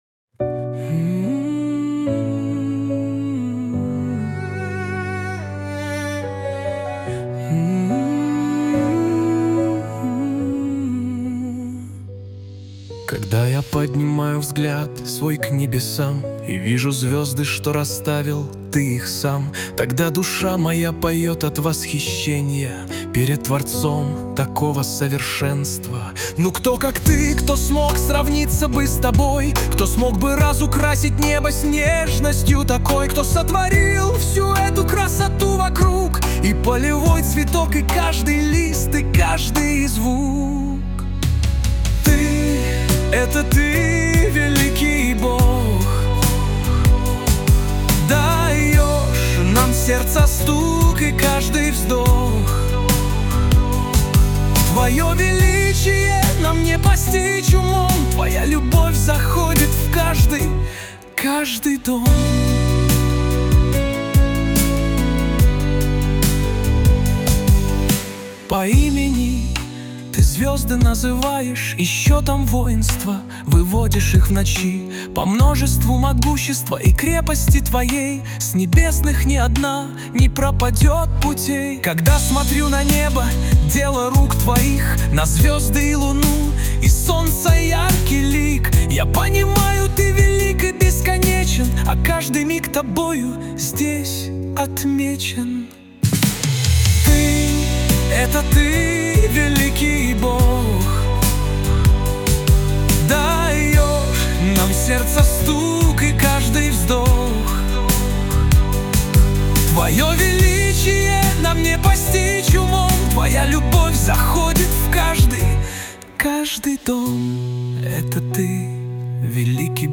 песня ai
270 просмотров 1001 прослушиваний 92 скачивания BPM: 71